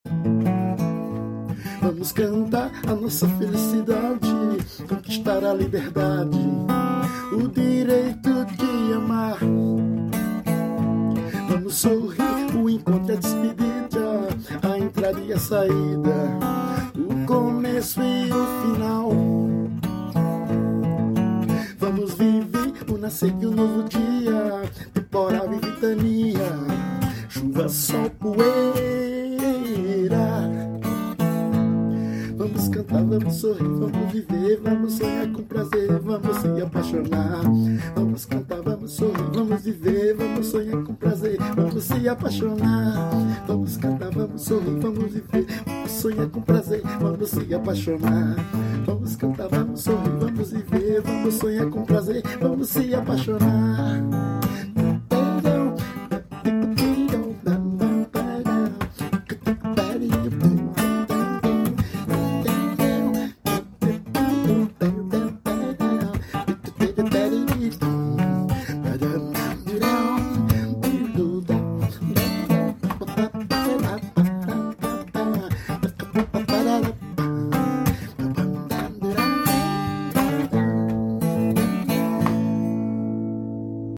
EstiloJazz